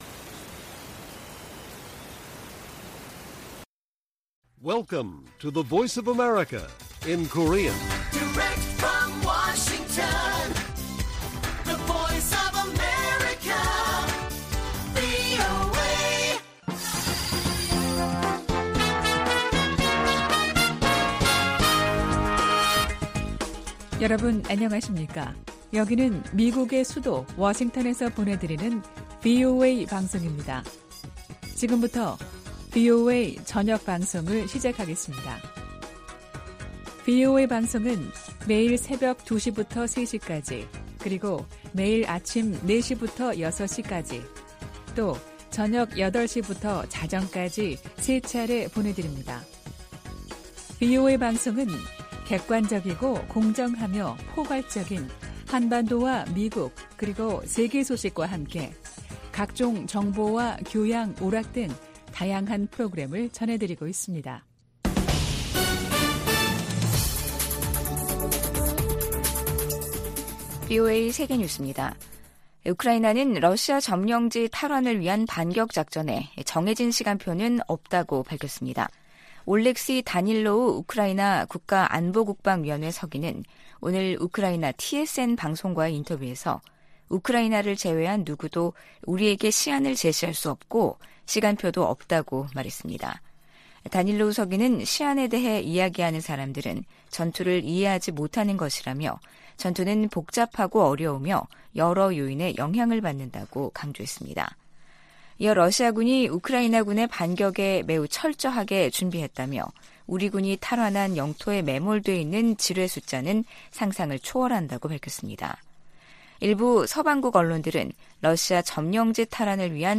VOA 한국어 간판 뉴스 프로그램 '뉴스 투데이', 2023년 8월 3일 1부 방송입니다. 북한이 무단 월북 미군 병사 사건과 관련해 유엔군사령부에 전화를 걸어왔지만 실질적인 진전은 아니라고 국무부가 밝혔습니다. 핵확산금지조약(NPT) 당사국들이 유일하게 일방적으로 조약을 탈퇴한 북한을 비판했습니다. 김영호 한국 통일부 장관이 현 정부에서 종전선언을 추진하지 않을 것이라고 밝혔습니다.